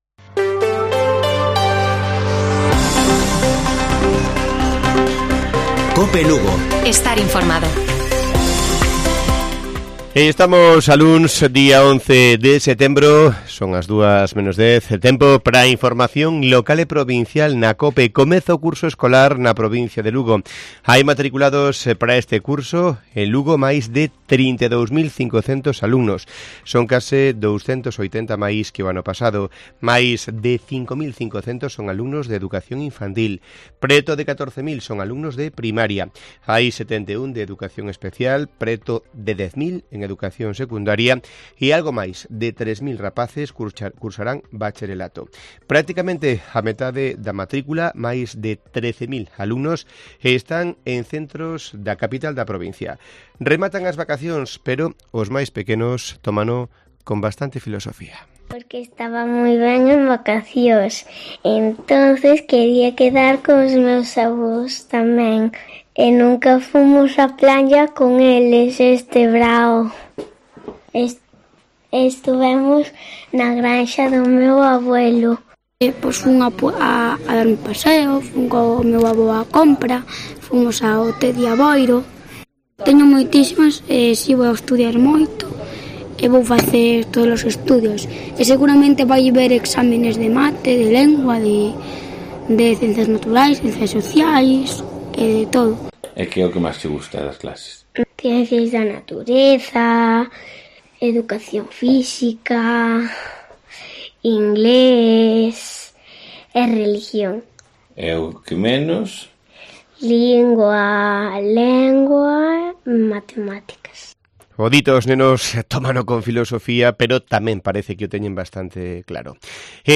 Informativo Mediodía de Cope Lugo. 11 de septiembre. 13:50 horas